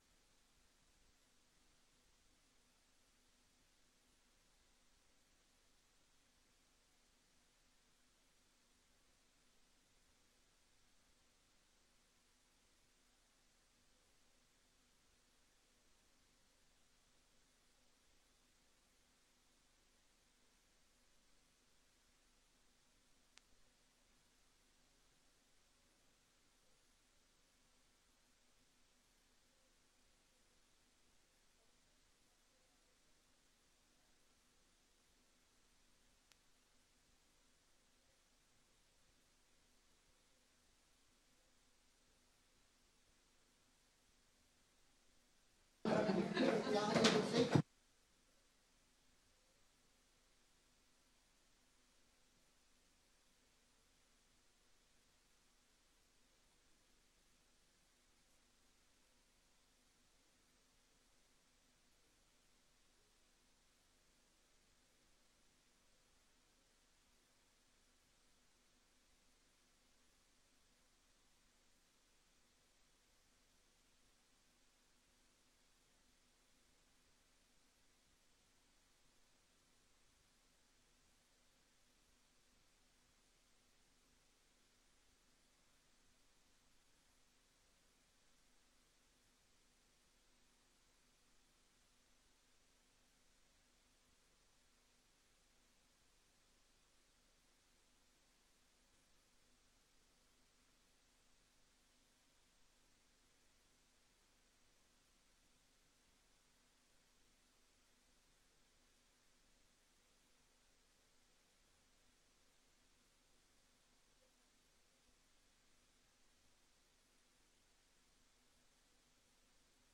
Download de volledige audio van deze vergadering
Voorzitter: Corrie Middelkoop
Locatie: Raadzaal